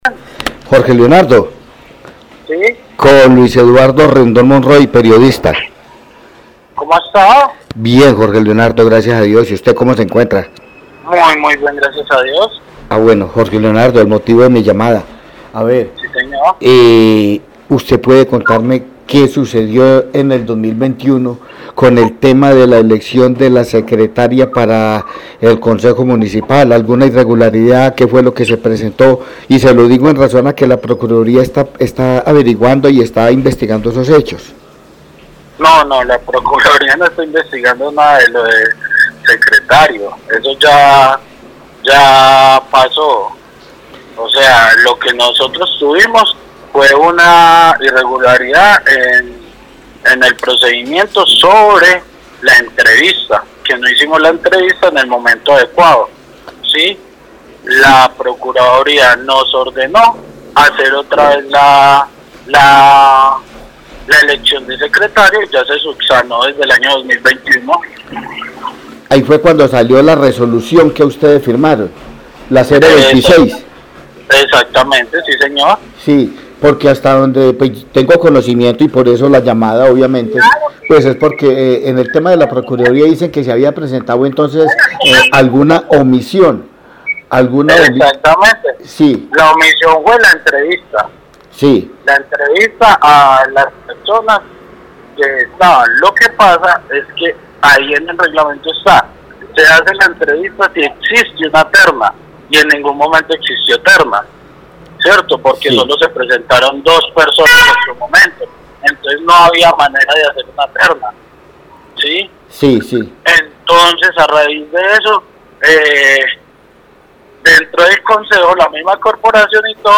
Audio entrevista del: Concejal Jorge Leonardo Flórez Parra